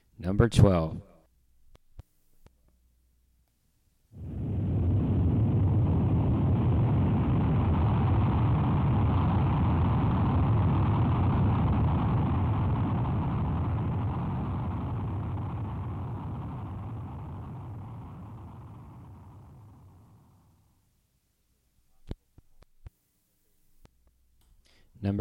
古老的地震 岩石滑梯 " G4312地震效应
描述：短而闷闷不清的皱褶渐渐消失。可用于各种隆隆声。 这些是20世纪30年代和20世纪30年代原始硝酸盐光学好莱坞声音效果的高质量副本。 40年代，在20世纪70年代早期转移到全轨磁带。我已将它们数字化以便保存，但它们尚未恢复并且有一些噪音。
Tag: 隆隆声 地震 经典